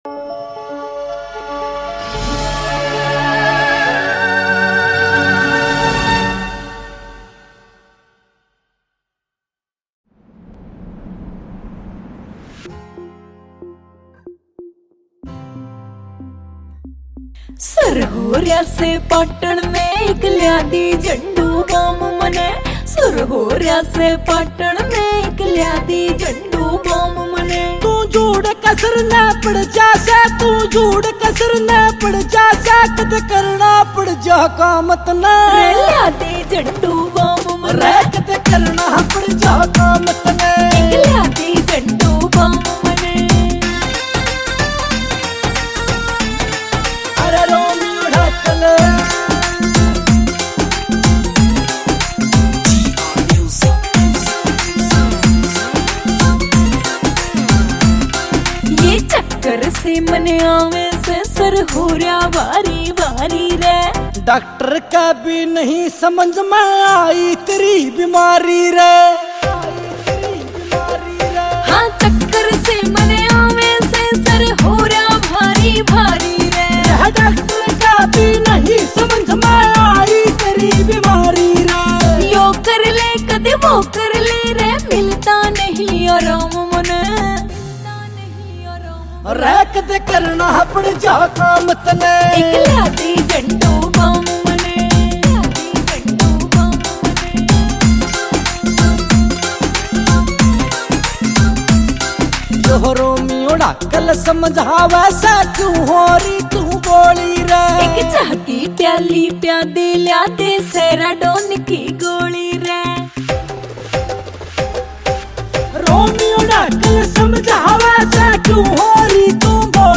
haryanvi song,